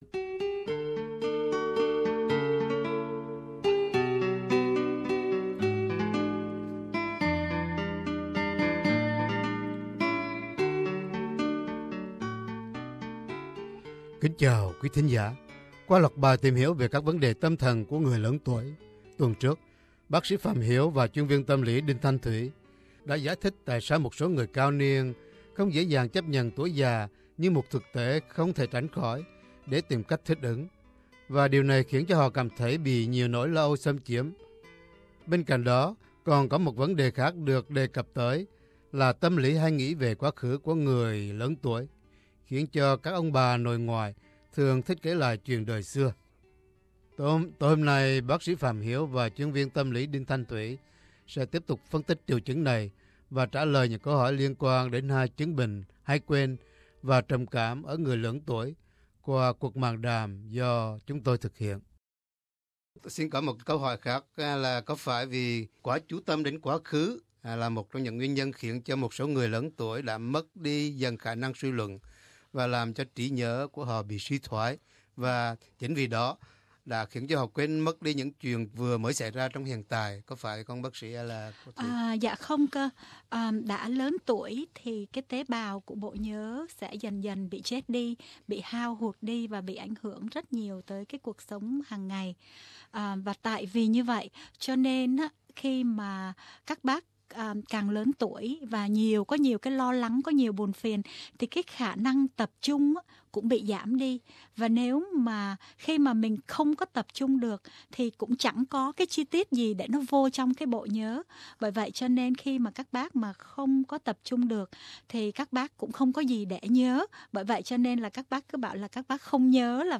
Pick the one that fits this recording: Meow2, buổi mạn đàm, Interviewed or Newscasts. buổi mạn đàm